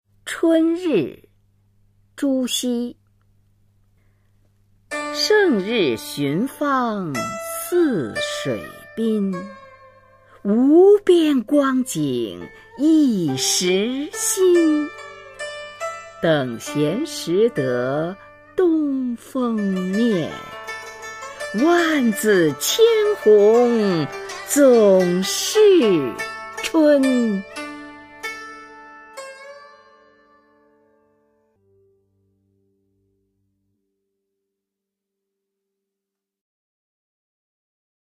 [宋代诗词诵读]朱熹-春日 宋词朗诵